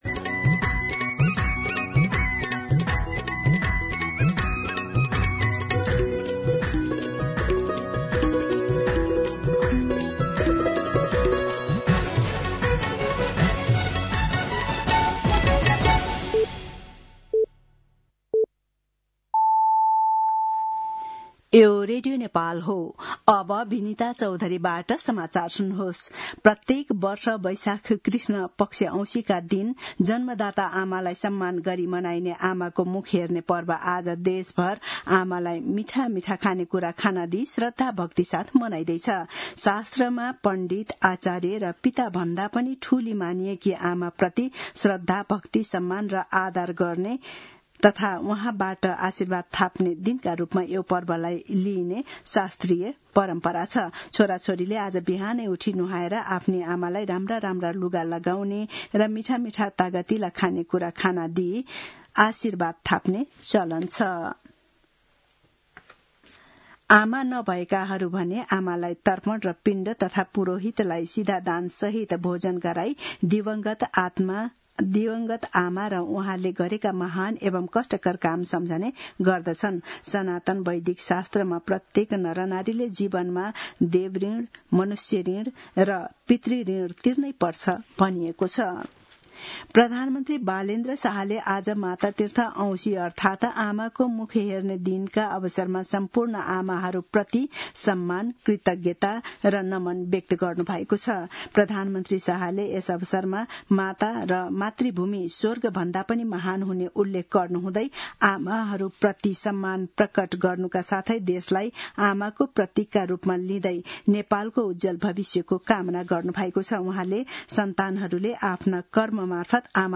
दिउँसो १ बजेको नेपाली समाचार : ४ वैशाख , २०८३